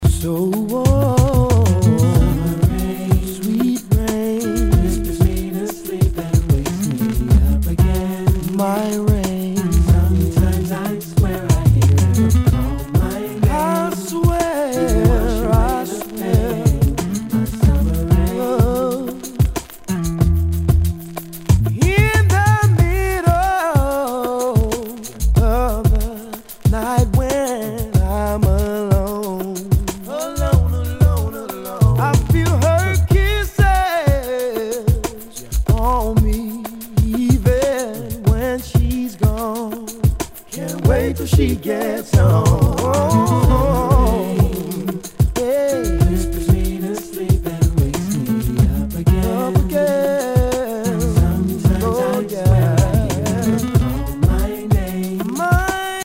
HIPHOP/R&B
全体にチリノイズが入ります